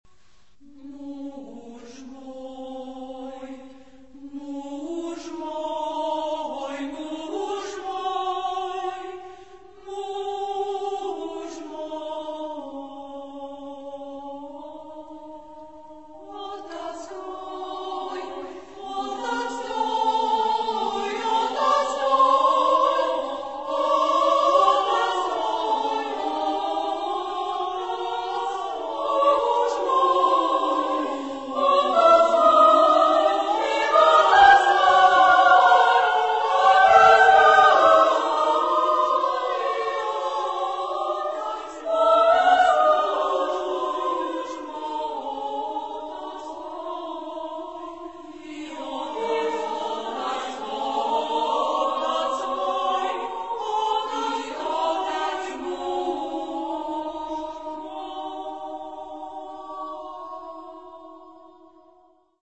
Genre-Stil-Form: weltlich ; zeitgenössisch
Charakter des Stückes: puzzle ; mäßig
Chorgattung: SMA  (3-stimmiger Frauenchor )
Solisten: Soprano (1) / Mezzo-soprano (1)  (2 Solist(en))
Tonart(en): Zweiter Modus von Messiaen